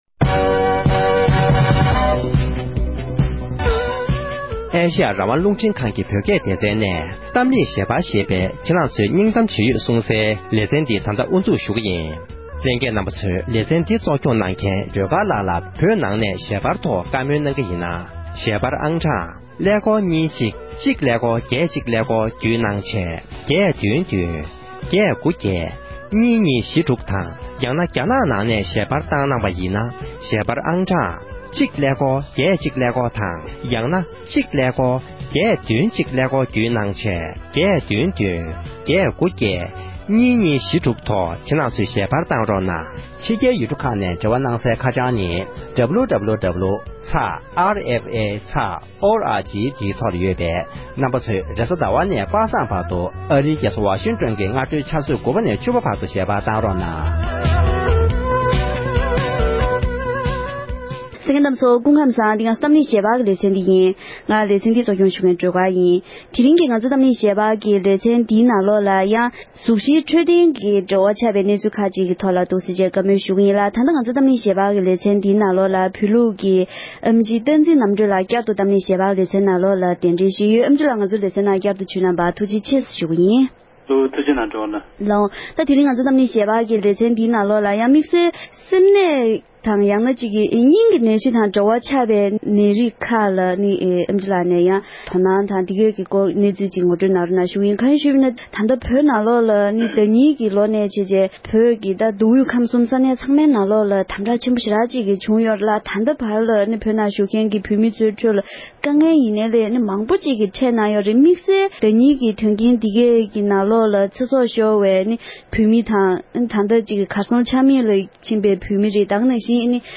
གཏམ་གླེང་ཞལ་པར
འབྲེལ་ཡོད་སྨན་པ་ཁག་ཅིག་གི་ལྷན་དུ